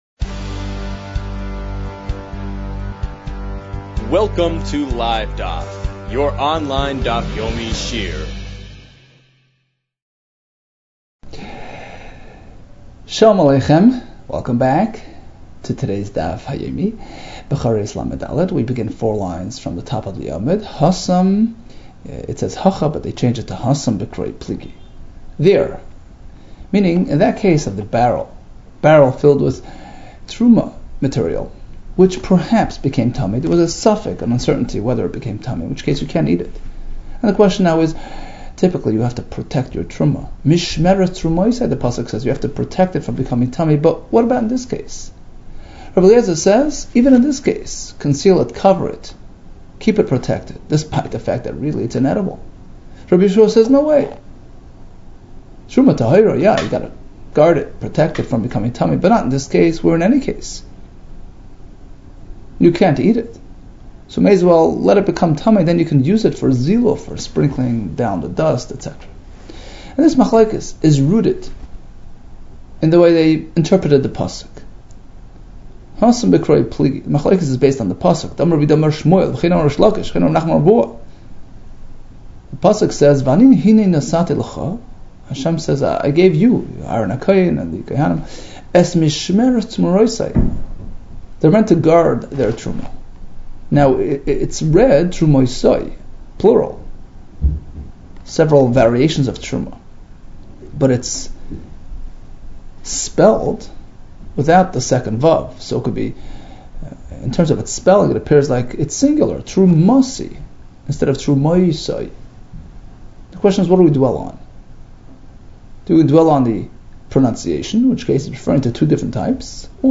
Bechoros 34 - בכורות לד | Daf Yomi Online Shiur | Livedaf